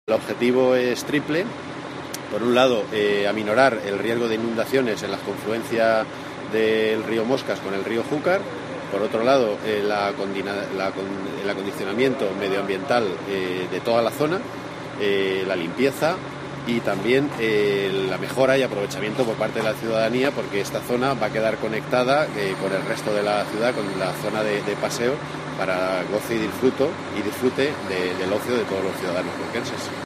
• Declaraciones del subdelegado del Gobierno en Cuenca, Juan Rodríguez Cantos